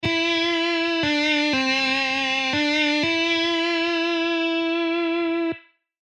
Pahoittelen kammottavaa tietokonesoundia.
Riffi 1